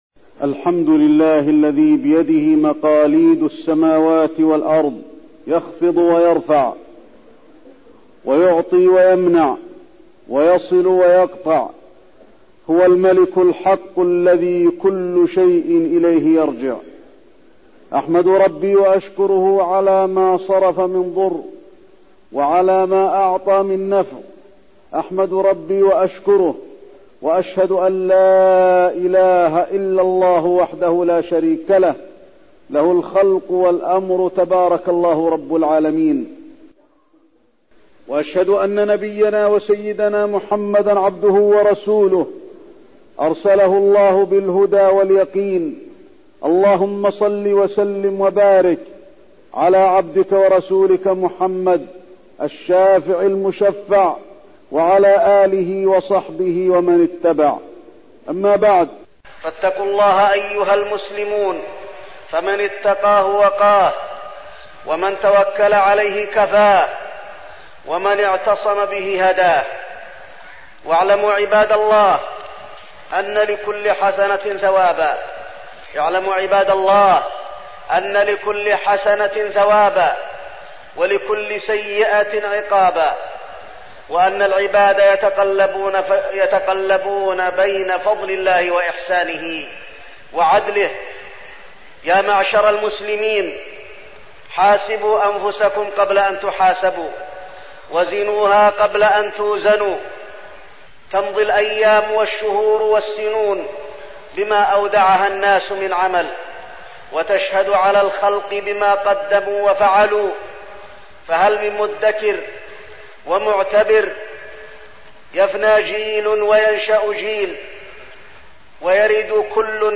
تاريخ النشر ٢٣ ذو الحجة ١٤١١ هـ المكان: المسجد النبوي الشيخ: فضيلة الشيخ د. علي بن عبدالرحمن الحذيفي فضيلة الشيخ د. علي بن عبدالرحمن الحذيفي الرجوع إلى الله والخوف منه